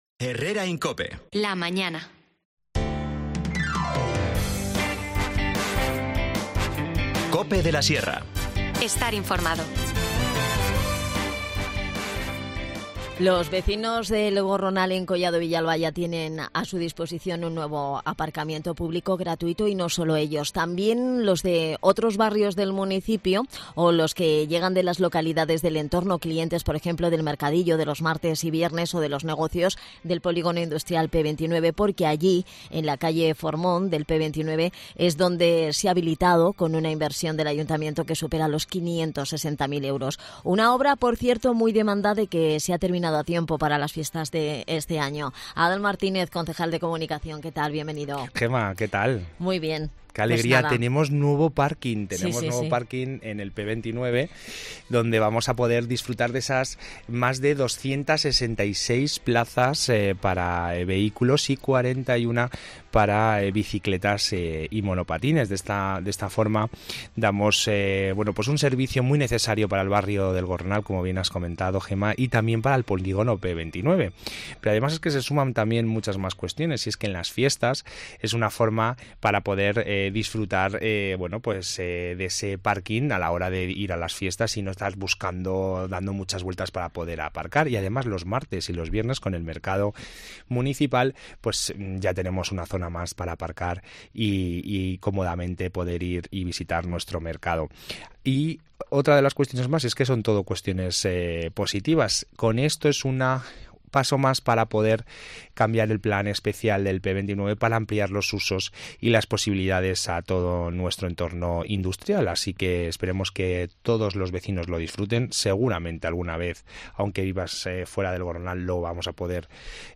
Es una de las cuestiones que abordamos en 'Collado Villalba, Capital de la Sierra' con Adan Martínez, concejal de Comunicación. Además nos adelanta todas las acciones formativas que han organizado para mejorar la empleabilidad de quienes buscan trabajo.